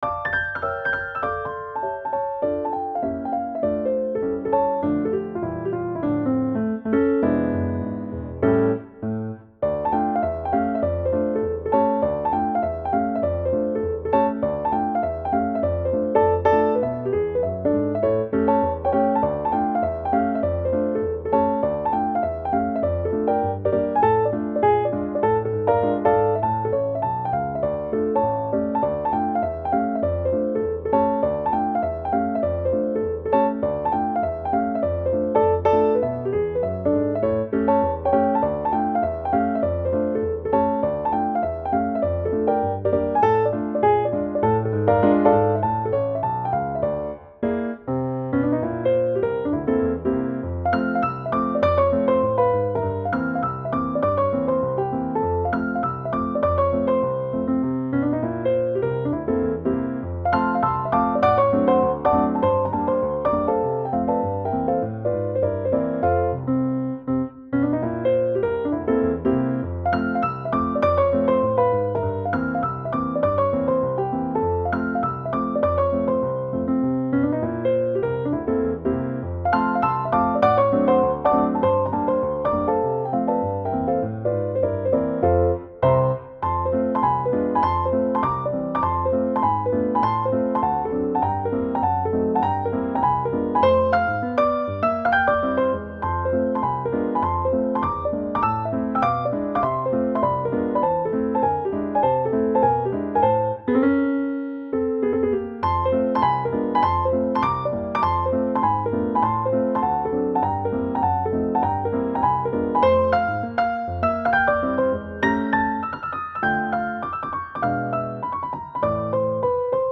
Stride piano